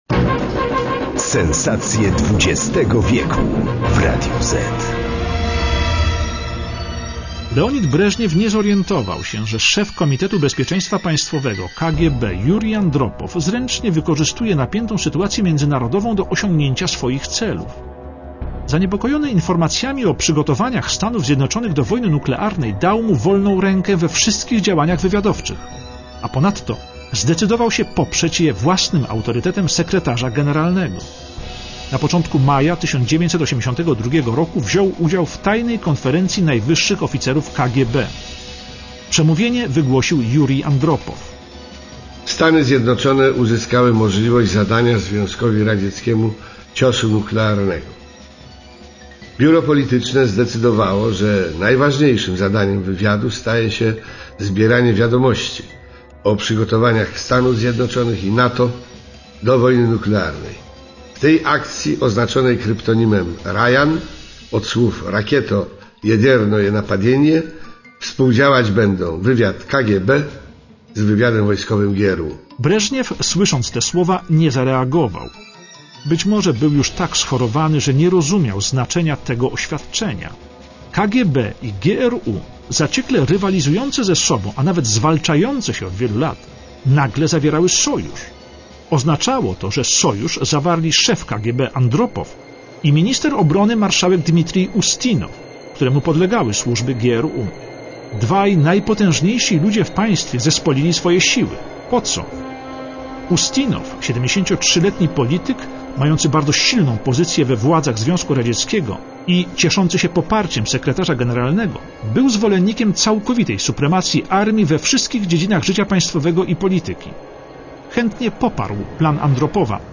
Aktorzy:
Jurij Andropow - Krzysztof Kowalewski